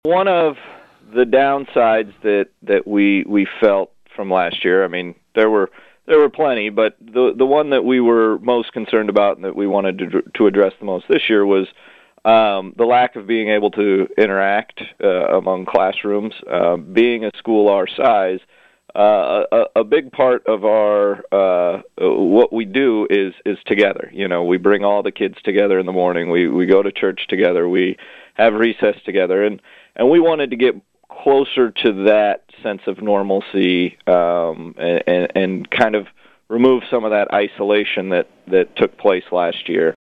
School administrators were part of separate interviews on KVOE the past few days to update their situations.